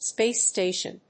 アクセントspáce stàtion